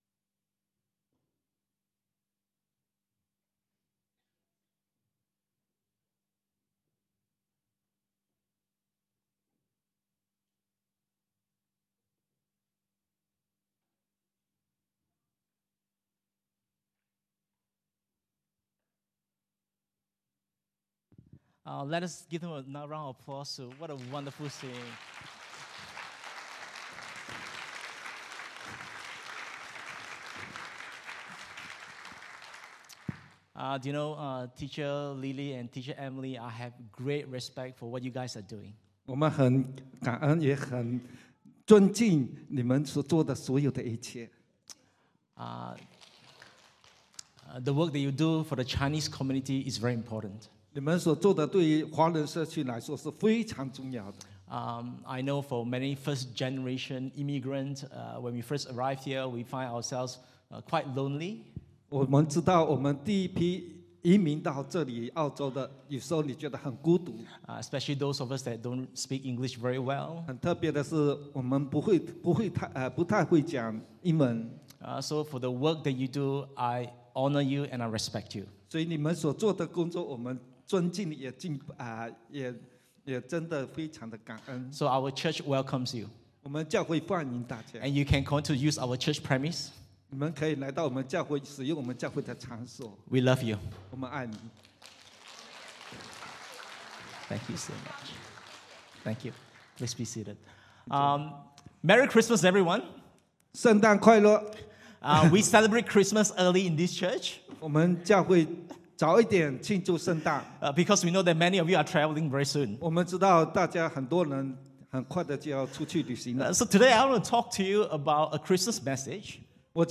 English Sermons | Casey Life International Church (CLIC)